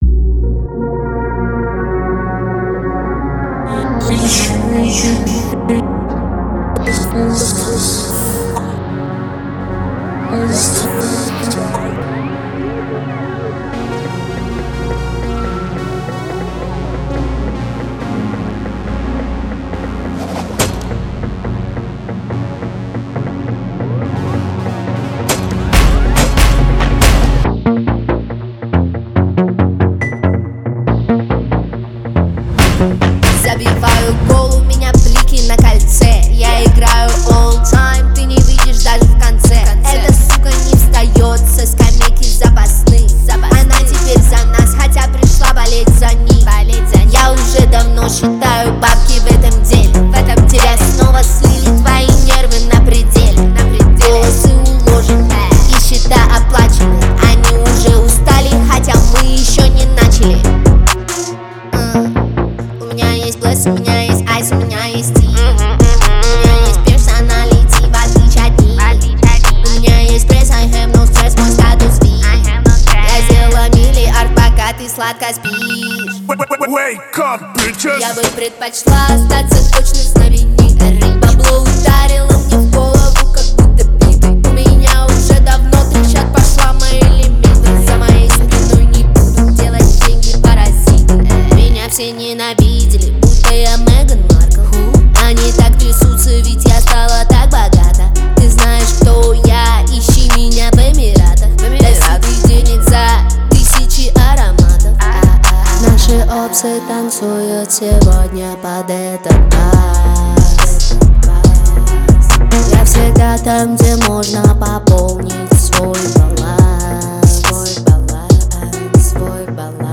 Категория Поп